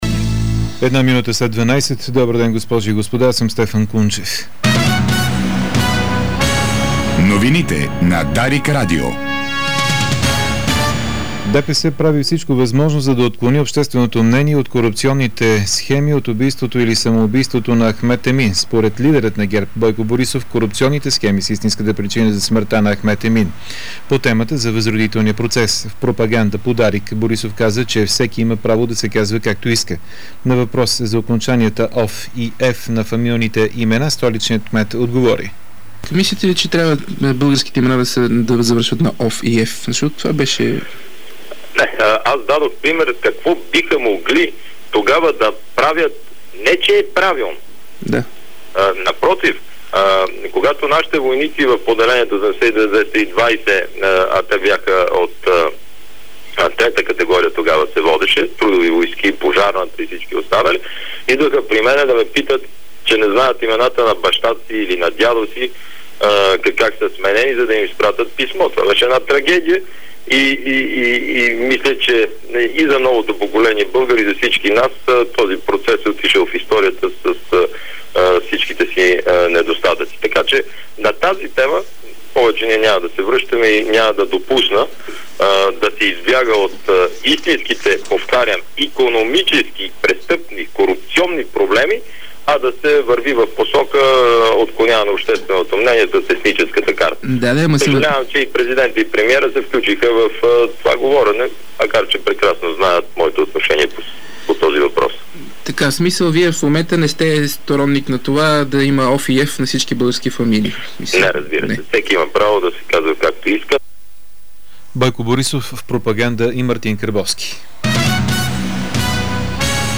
Обедна информационна емисия - 02.11.2008